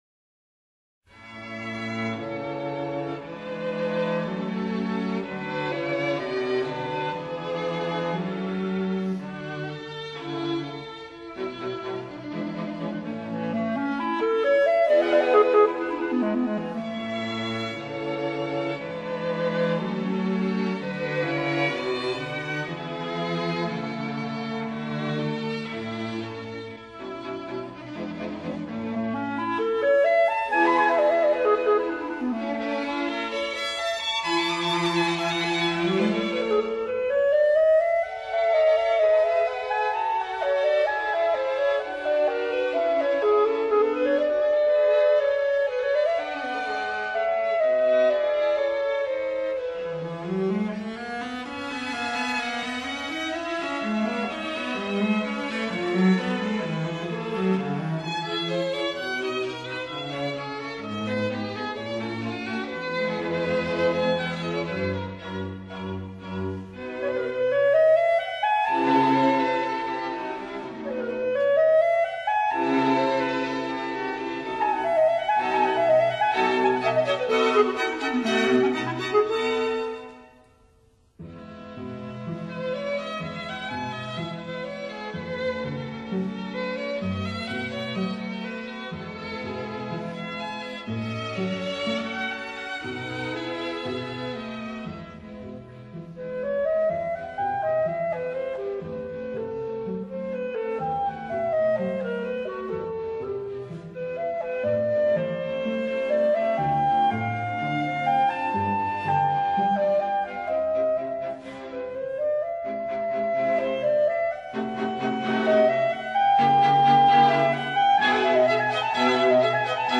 in A major, KV.581
Karl Leister, Clarinet / The Vienna String Quartet
오히려 수채화처럼 맑고, 그리고 우아하면서도 독특한 애수가 애잔하게 흐르는 감동이 있습니다.
유려하게 흘러가는 현악 선율을 바탕으로 로맨티시즘을 가득 담은 우수의 클라리넷 선율은
부드러우면서도 감미로운 음색으로 표현할 수 없는 정서를 찬란하게 샘솟게 합니다.
편성은 클라리넷, 바이올린2, 그리고 비올라와 첼로를 사용했습니다.
Karl Leister, Clarinet
독일 전통의 차분하게 가라앉은 은근한 음향이 무척 매력적입니다.